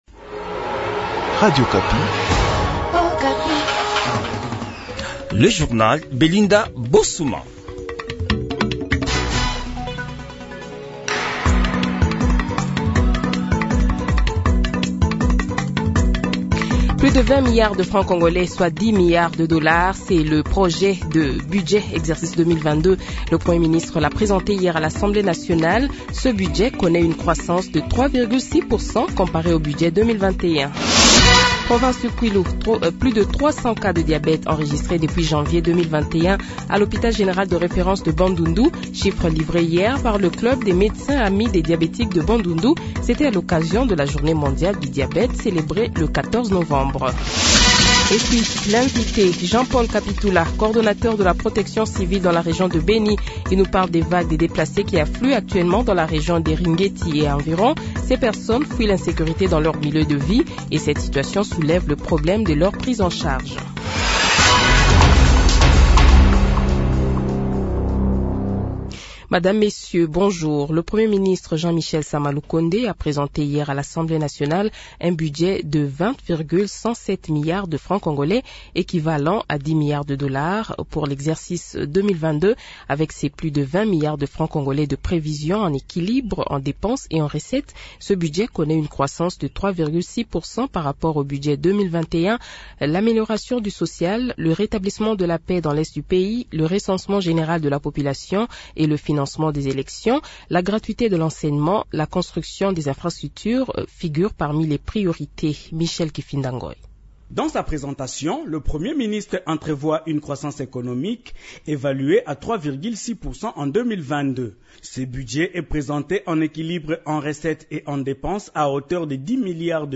Le Journal de 12h, 16 Novembre 2021 :